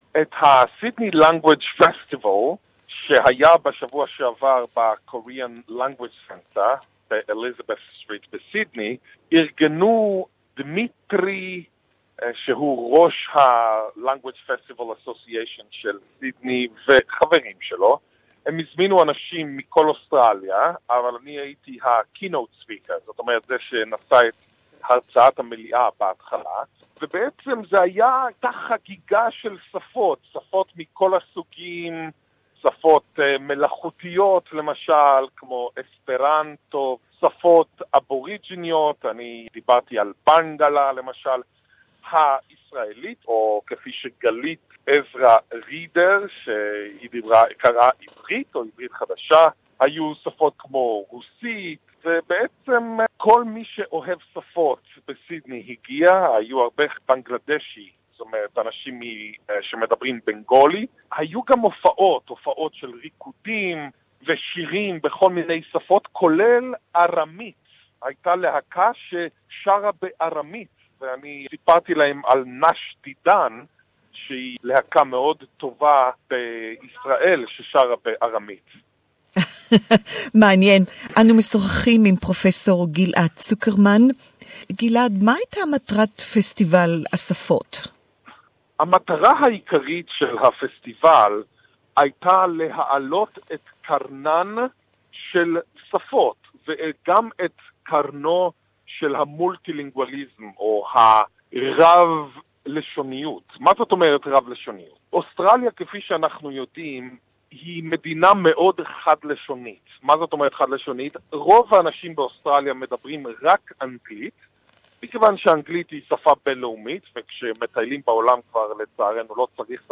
Ghil'ad Zuckermann Sydney Language Festival, Hebrew interview